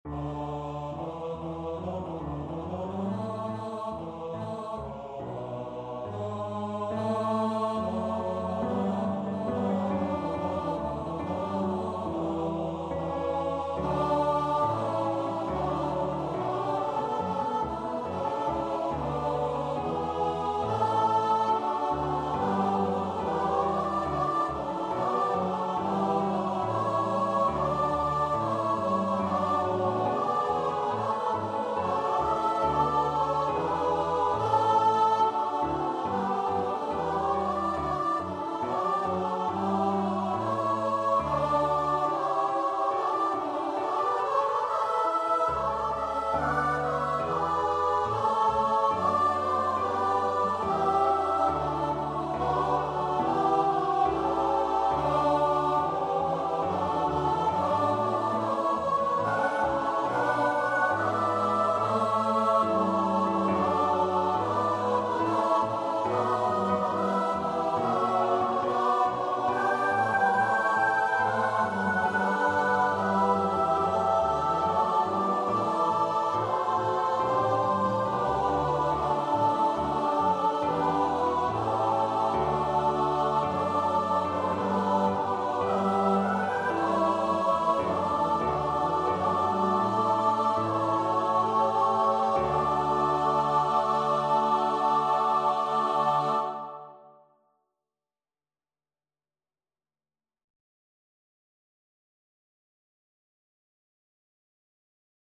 Sicut locutus est (Magnificat in D major, BWV 243) Choir version
Free Sheet music for Choir (SSATB)
Choir  (View more Intermediate Choir Music)
Classical (View more Classical Choir Music)